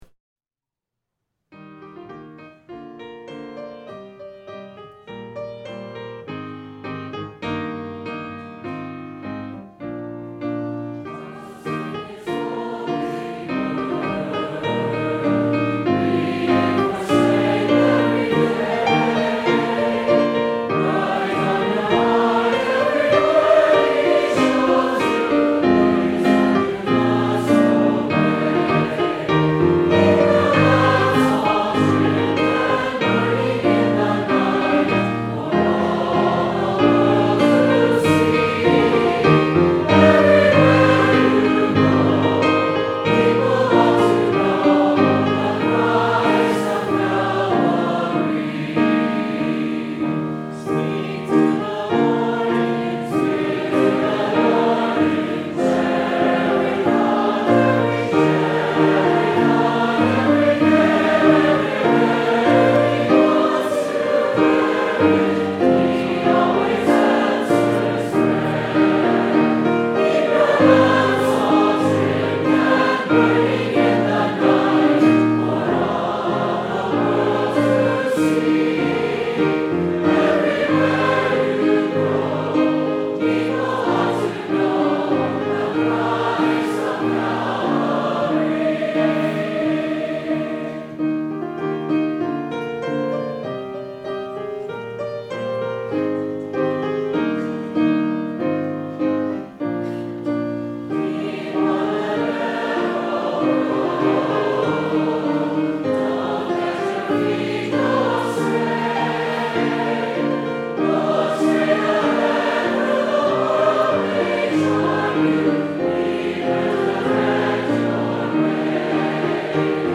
Two Part Mixed
Choral
Anthem
Church Choir